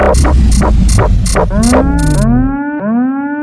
shutdown.ogg